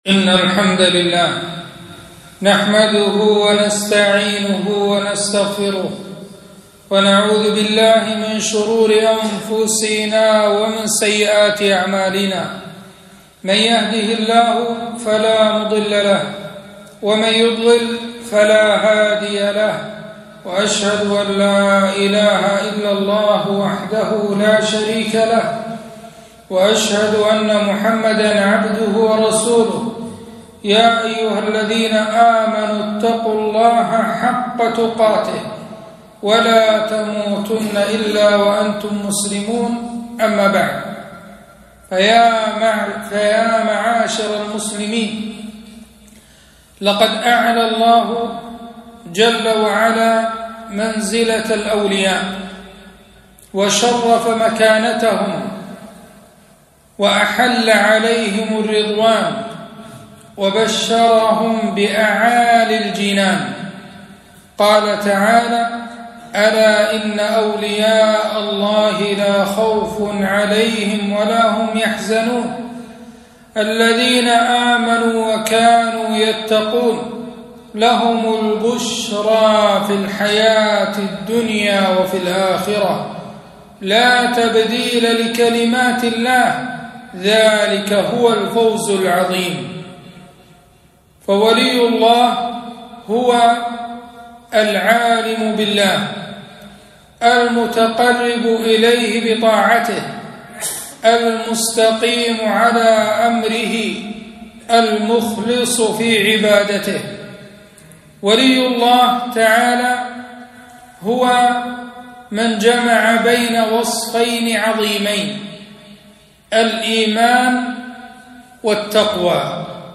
خطبة - من عاد لي وليا فقد أذنته بالحرب